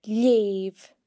The slender L sound is made by pressing your tongue against the roof of your mouth (palate) while pronouncing the letter, and occurs when the L is before or after an e or i. The slender L can be heard in leugh (read):